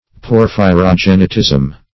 Porphyrogenitism \Por`phy*ro*gen"i*tism\, n. [LL. porphyro